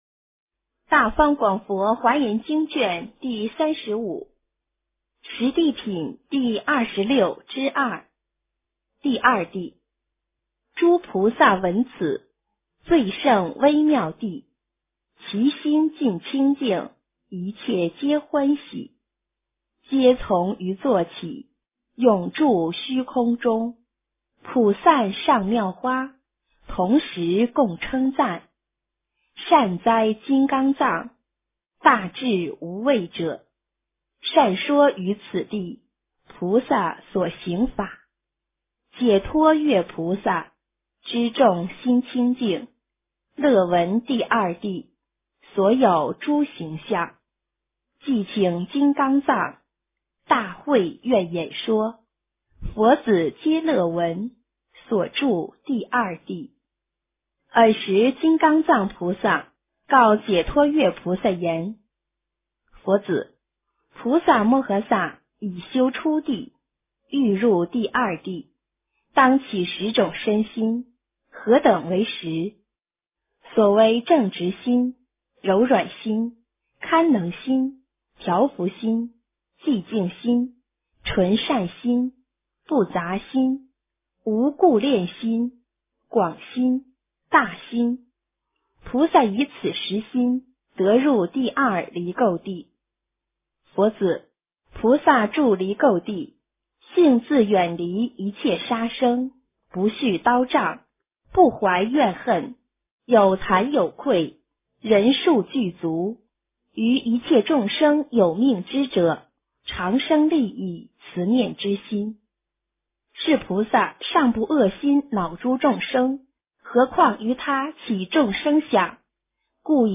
华严经35 - 诵经 - 云佛论坛